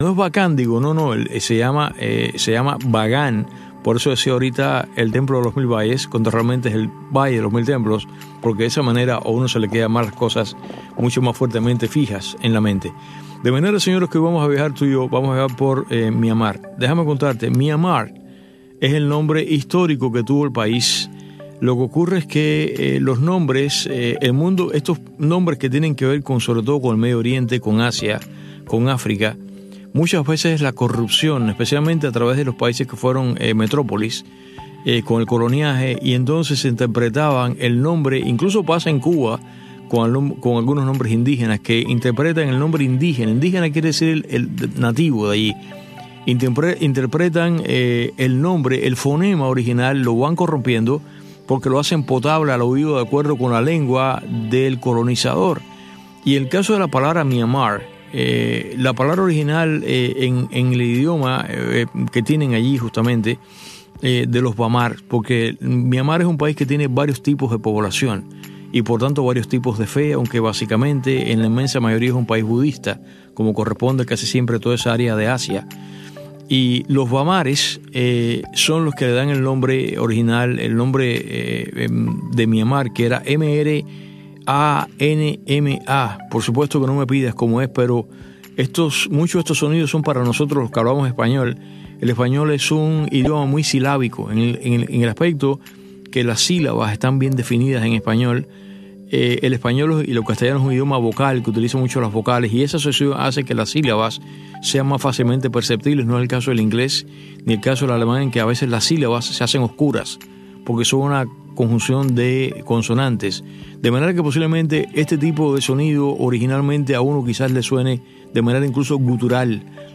conecta cada día con sus invitados en la isla en este espacio informativo en vivo, que marca el paso al acontecer cubano.